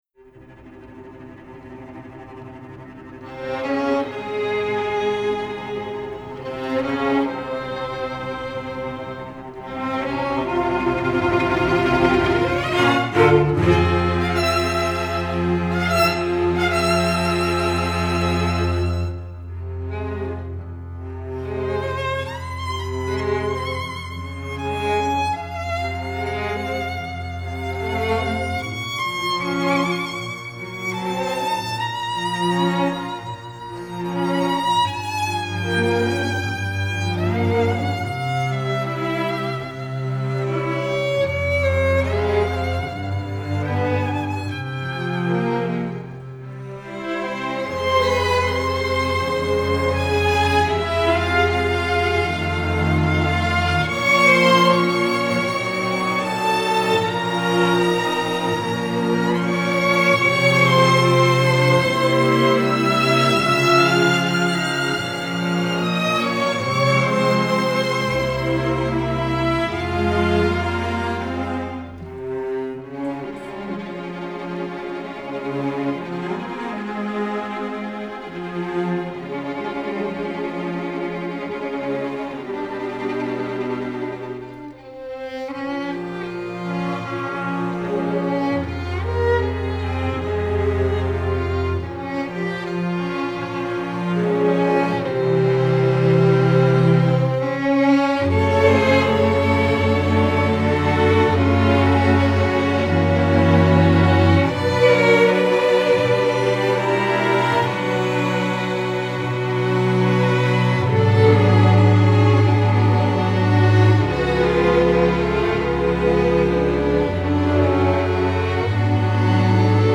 film/tv, movies